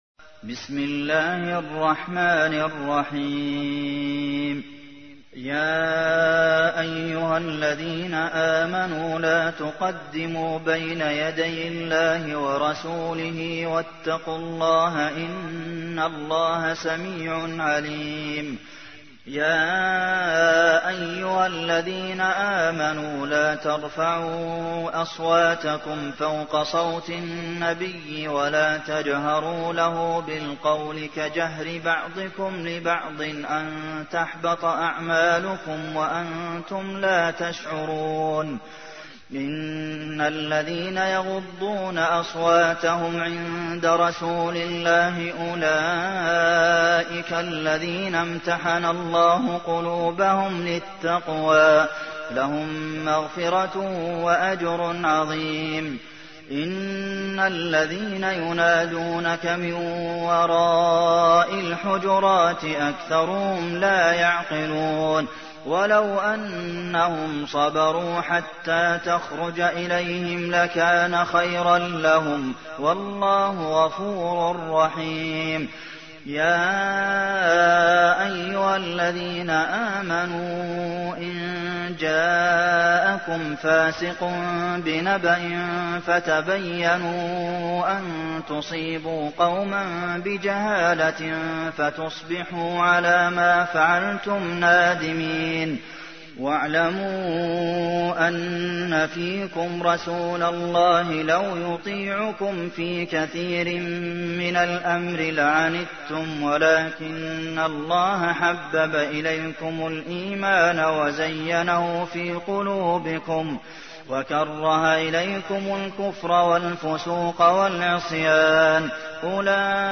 تحميل : 49. سورة الحجرات / القارئ عبد المحسن قاسم / القرآن الكريم / موقع يا حسين